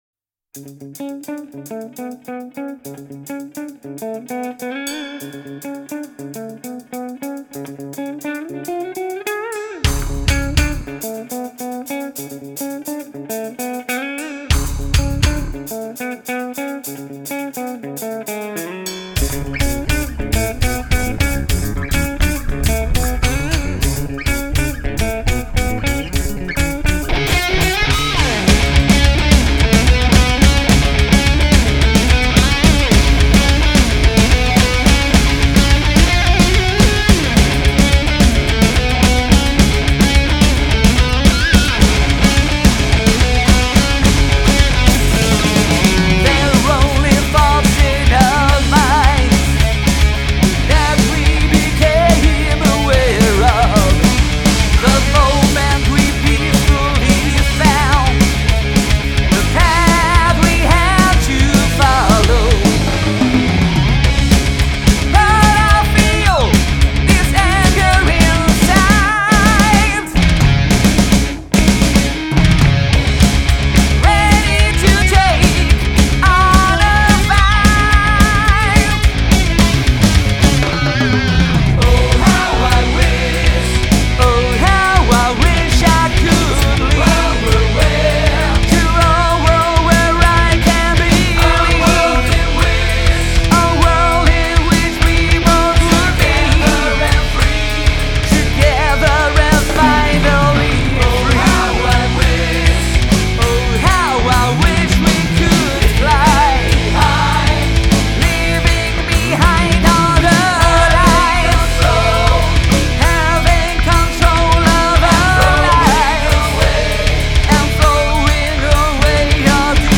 heavy metal Iles Canaries